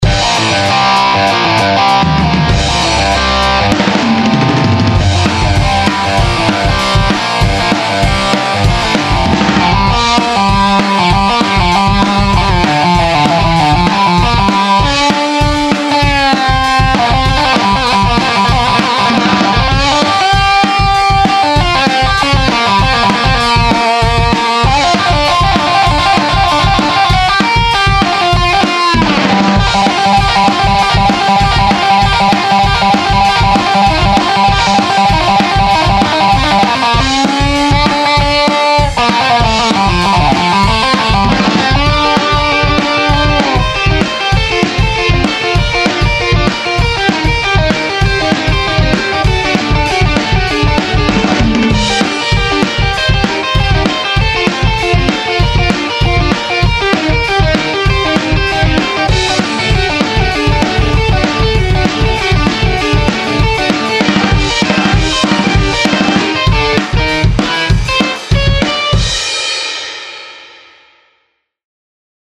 ●LesPaulP90●Telecaster